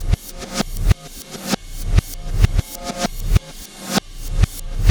Black Hole Beat 23.wav